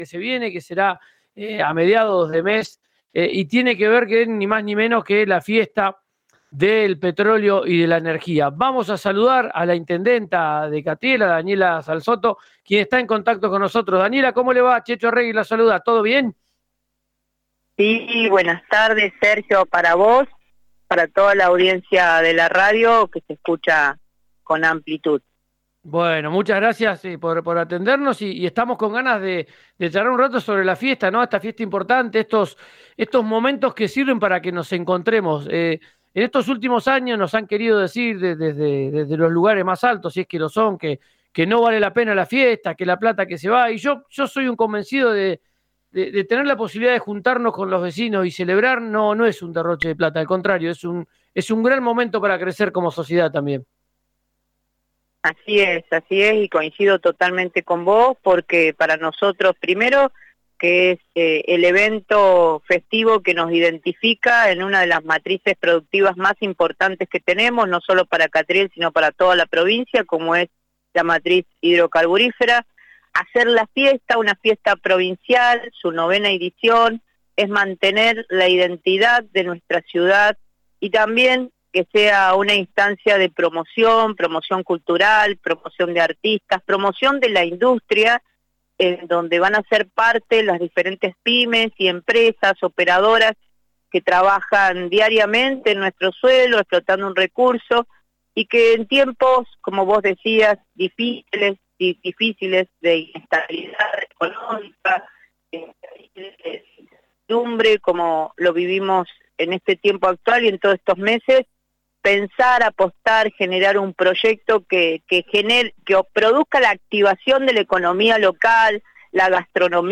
Escuchá a Daniela Salzotto en MARCA PATAGONIA por RÍO NEGRO RADIO:
daniela-intendenta-catriel.mp3